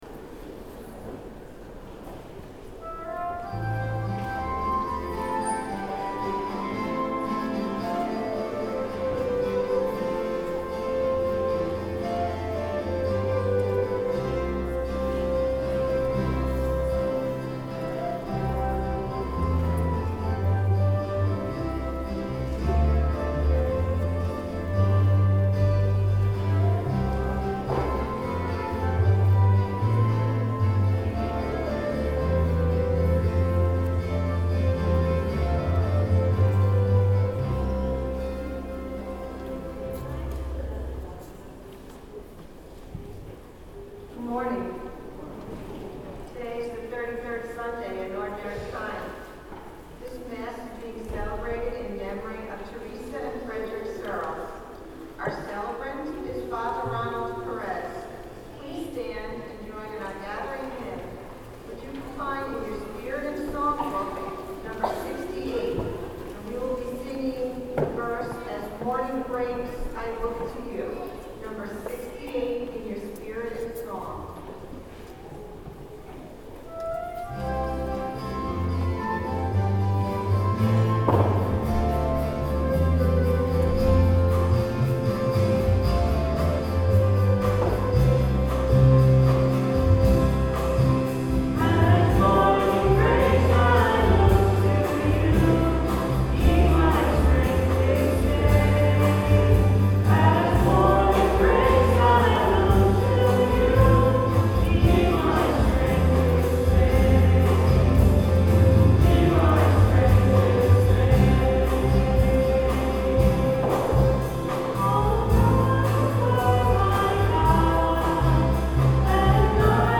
11/15/09 10:30 Mass Recording of Music - BK1030
Note that all spoken parts of the Mass have been removed from this sequence. (Distortion fixed)